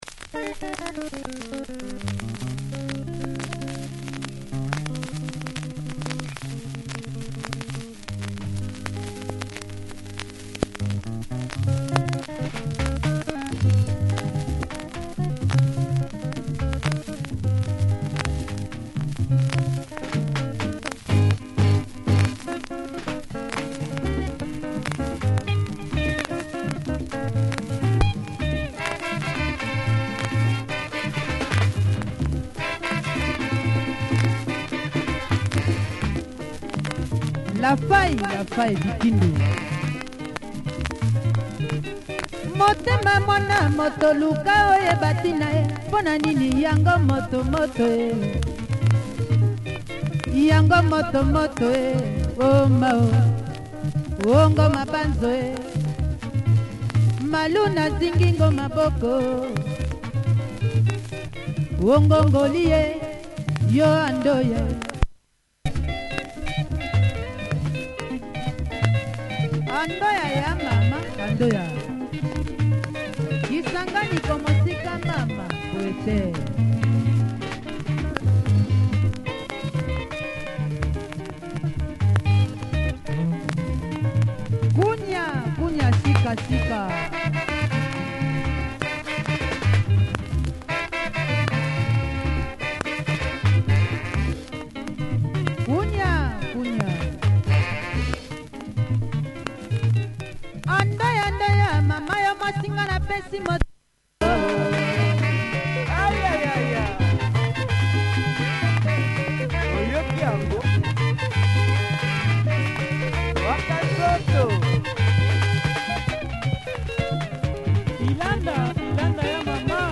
has a few marks that affect play